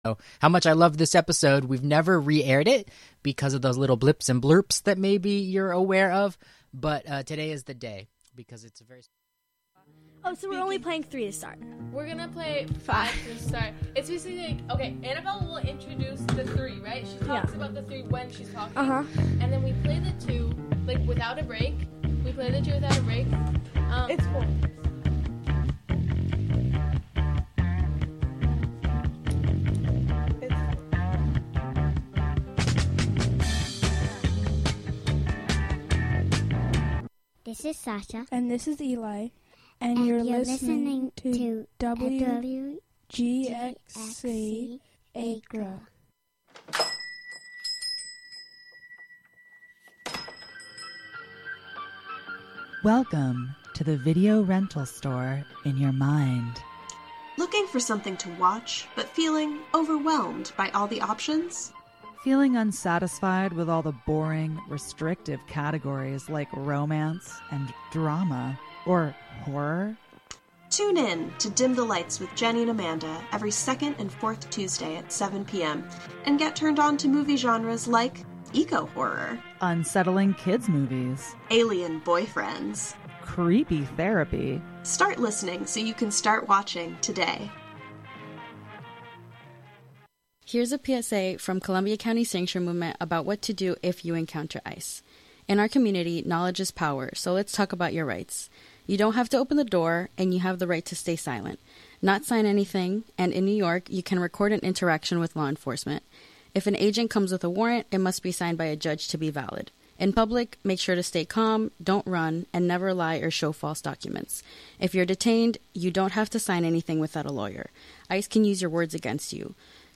The show will contain eclectic music, discussion, and interviews with invited guests. This is a program hosted by 3 teenage girls from Columbia county. "Auditions" features prospective WGXC volunteer programmers trying out their proposed radio programs on air for listener feedback.